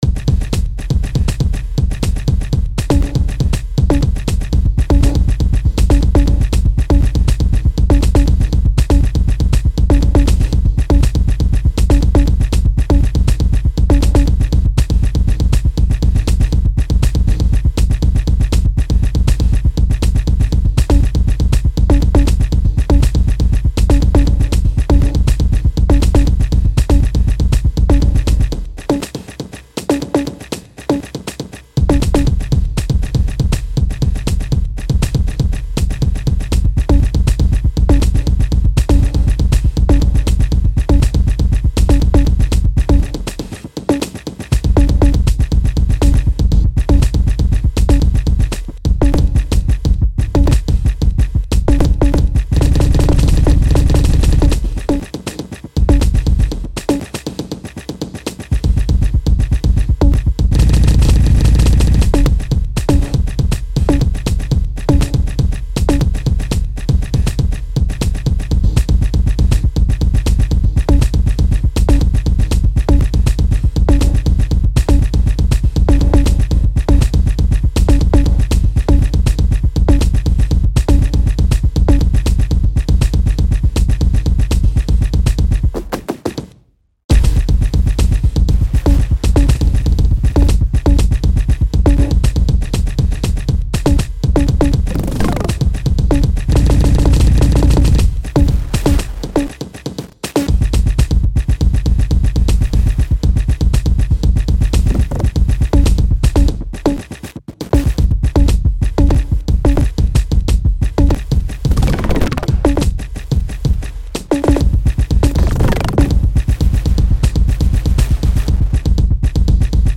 A bunch of kick samples sequenced and mangled by Tidalcycles